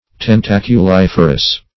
Search Result for " tentaculiferous" : The Collaborative International Dictionary of English v.0.48: Tentaculiferous \Ten`ta*cu*lif"er*ous\, a. [Tentaculum + -ferous.]
tentaculiferous.mp3